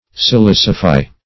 Silicify \Si*lic"i*fy\, v. i.